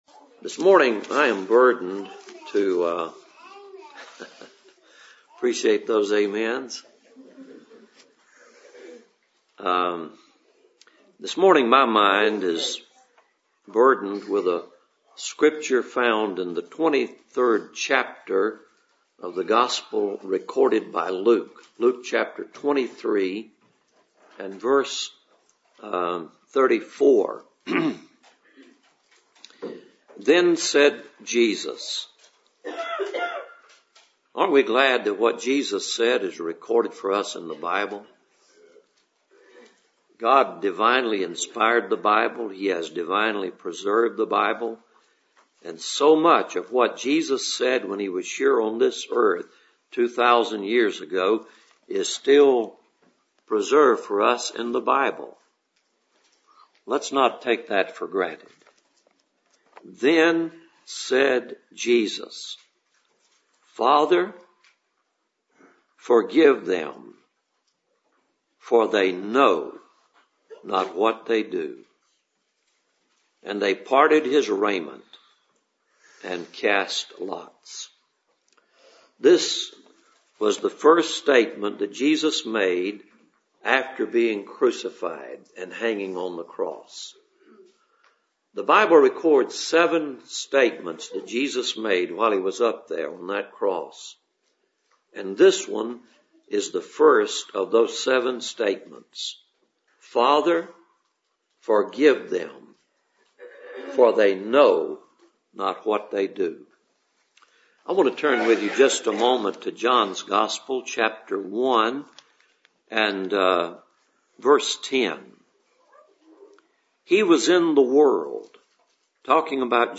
Passage: Luke 23:34 Service Type: Cool Springs PBC Sunday Morning